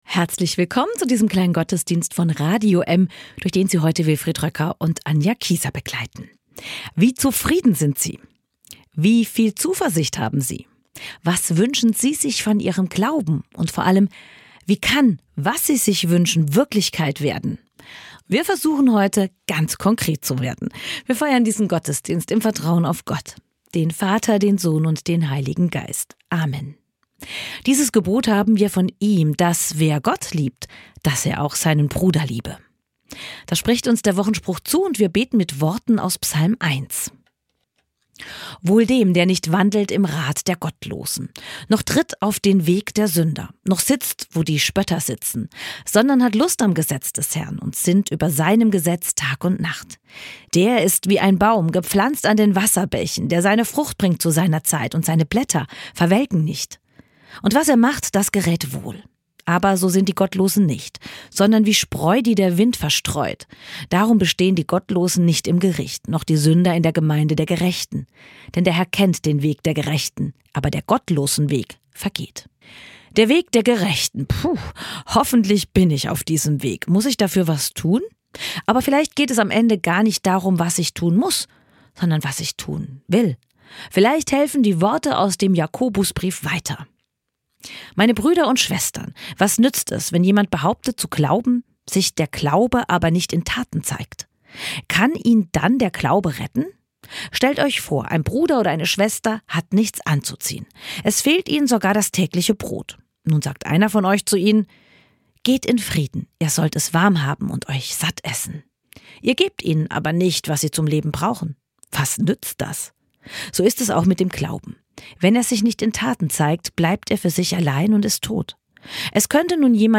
Ich habe irgendwie keine Lust darauf eine weitere Predigt zu halten, in der ich versuche, diese beiden Positionen irgendwie zusammen zu bringen.